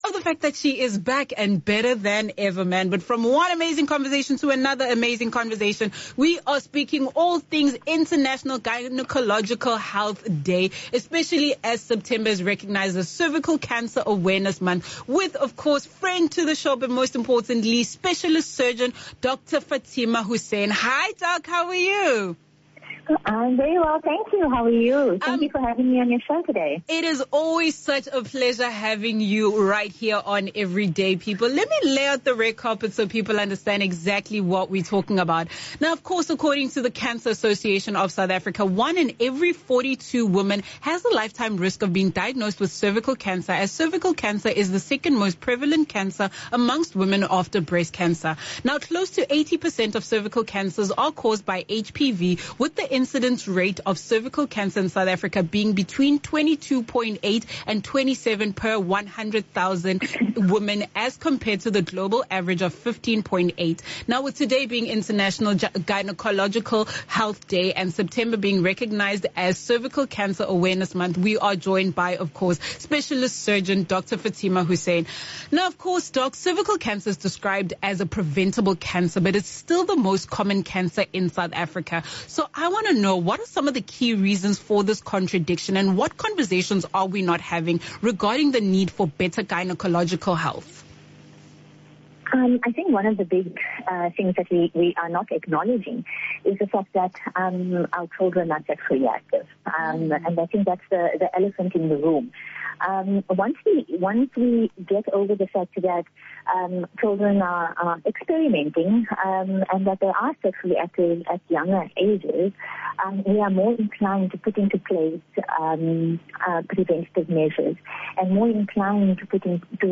With kind permission from Bush Radio, the full interview is available to listen to below.